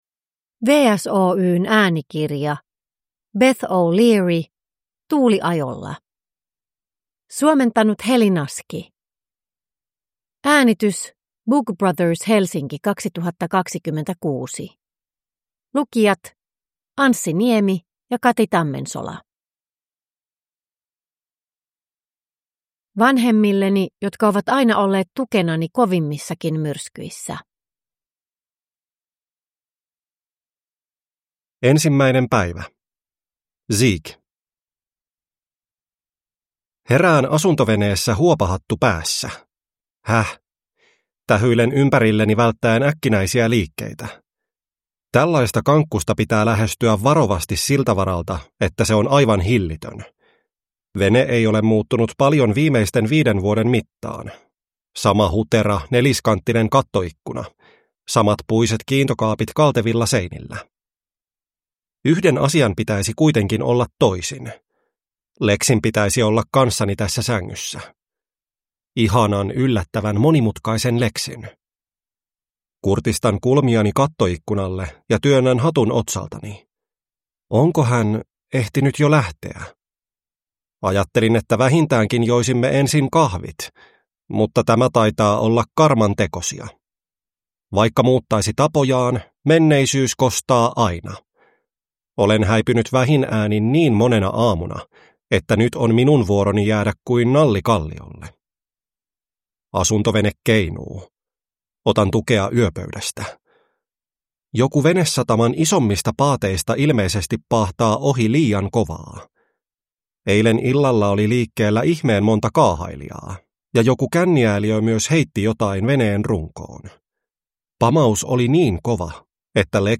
Tuuliajolla – Ljudbok